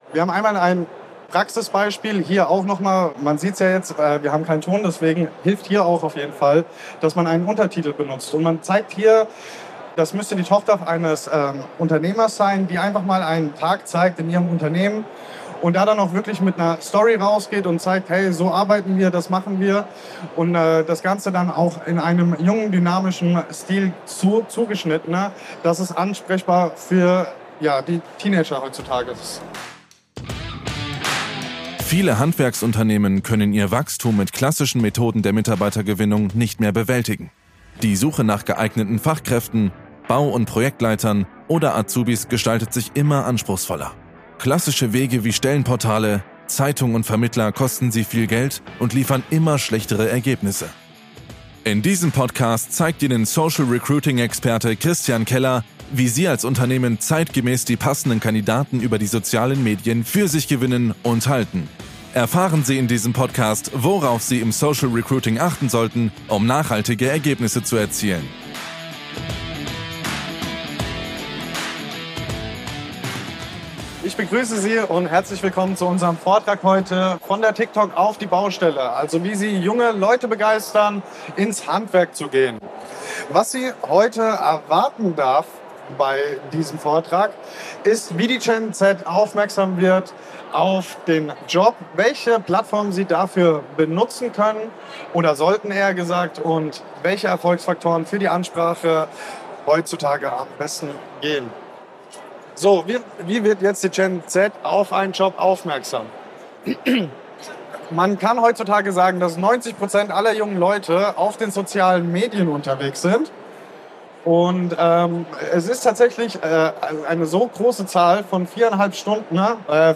In diesem Vortrag zeigt das Team von Kellerdigital, wie Handwerksbetriebe die Gen Z erfolgreich für Ausbildungsplätze begeistern – von TikTok bis WhatsApp.